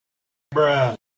bruh.mp3